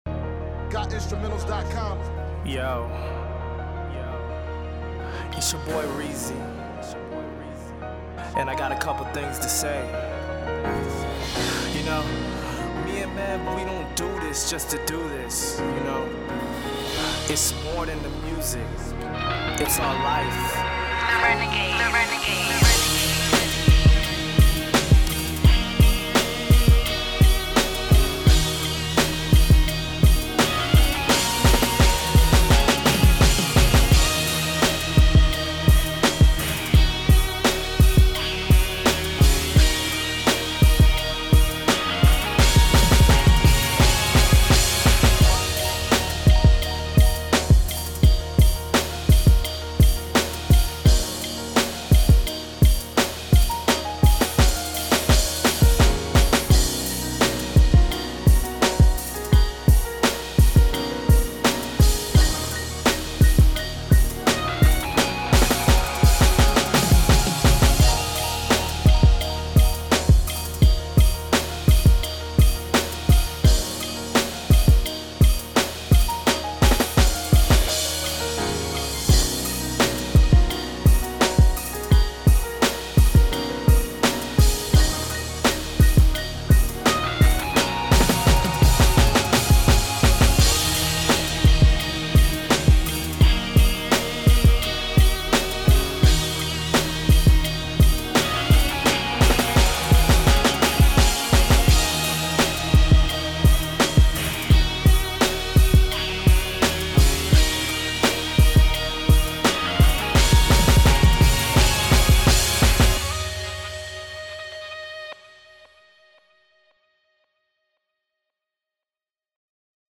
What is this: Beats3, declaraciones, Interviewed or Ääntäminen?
Beats3